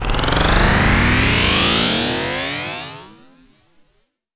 SCIFI_Up_01_mono.wav